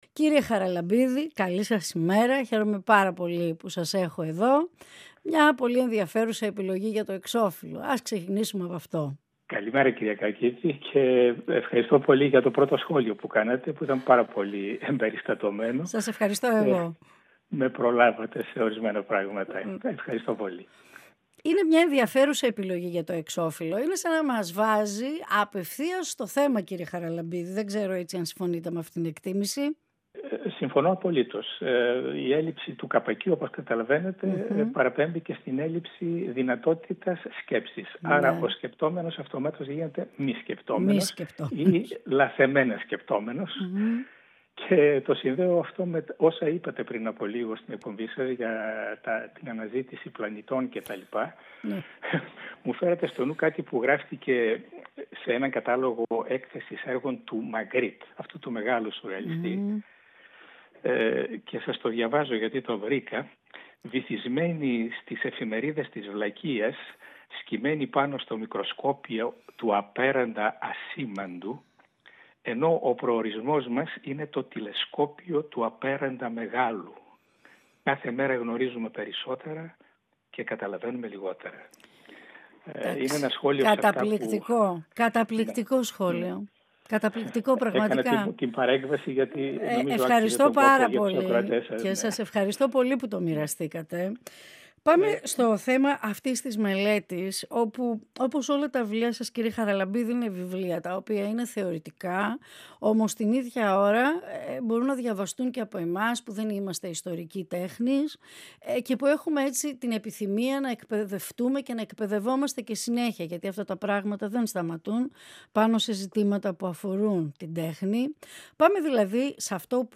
Ολιγη Κινηση του Δρομου και των Μαγαζιων Συνεντεύξεις ΕΡΤ3